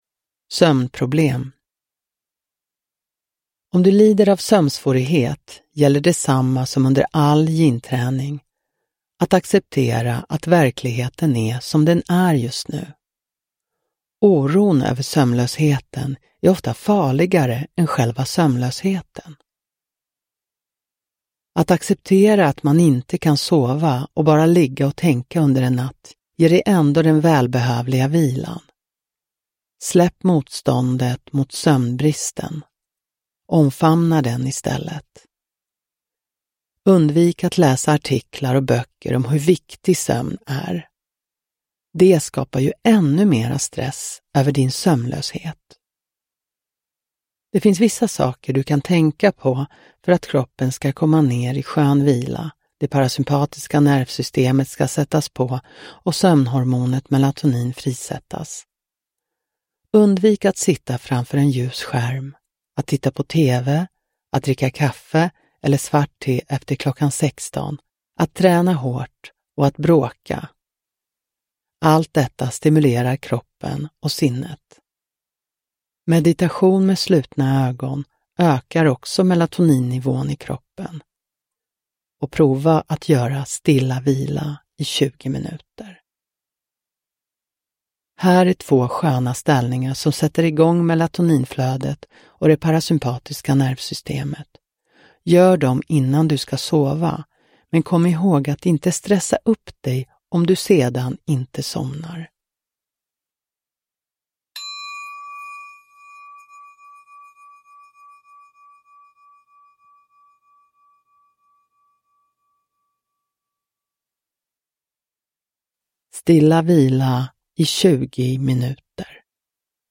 Sömnproblem – Ljudbok – Laddas ner